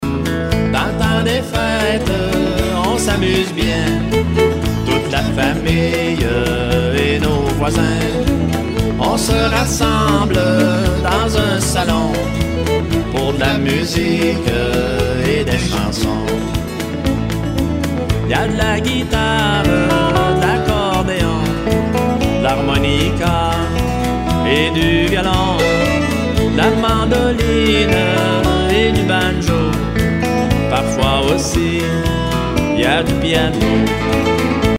Enregistrement au studio